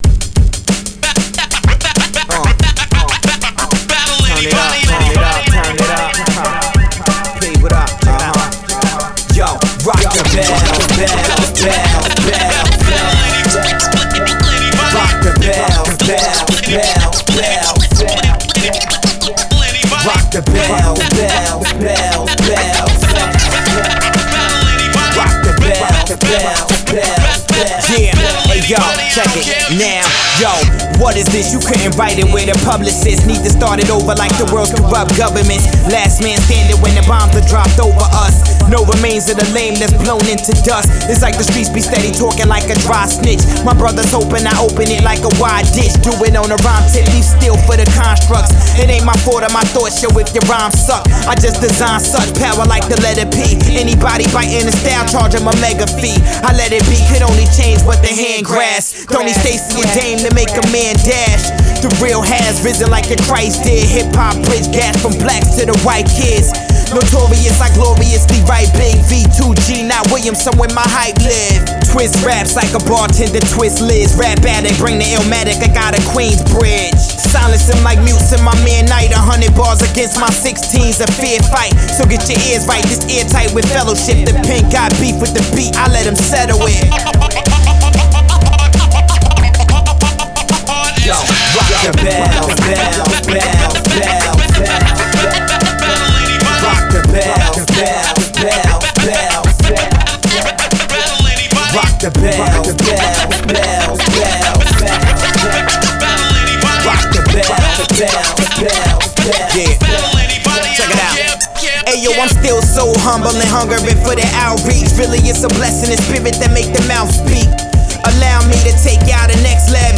an emcee & dj group